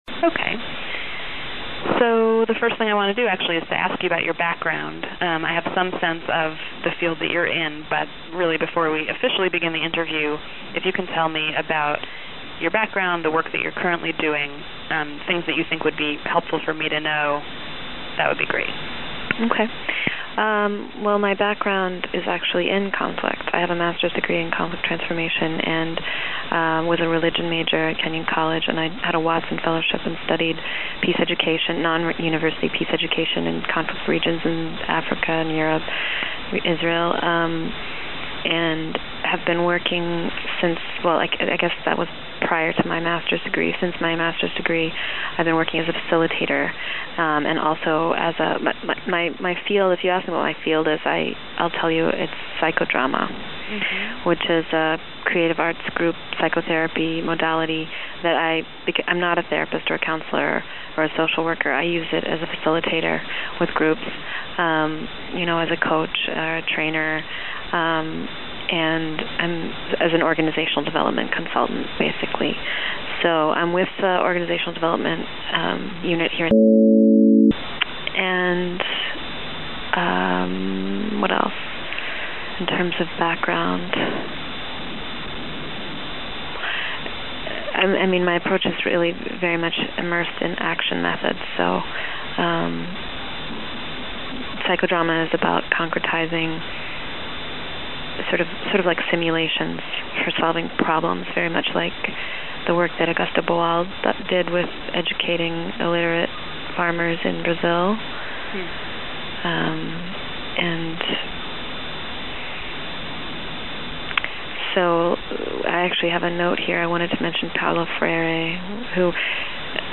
Listen to Full Interview This rough transcript provides a text alternative to audio.